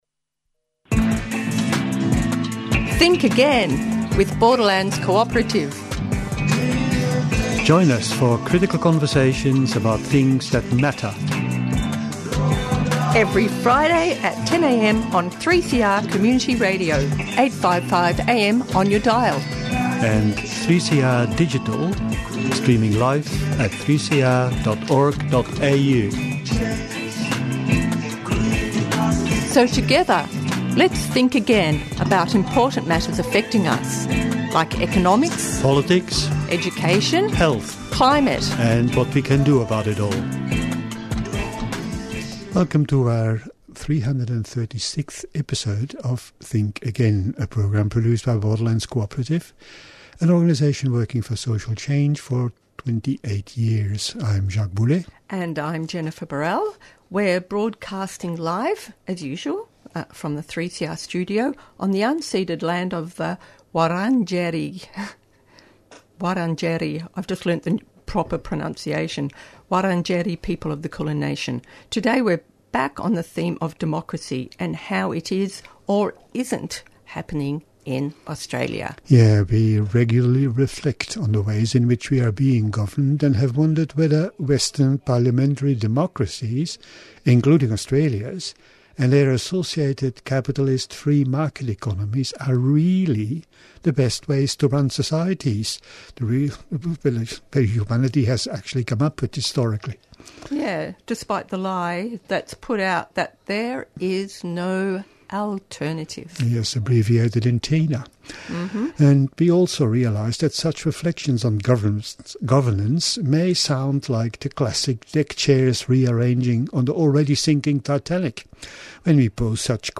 Tweet Think Again Friday 10:00am to 10:30am Think Again offers weekly conversations and reflections about current events, trends and public pronouncements on contemporary and emerging issues.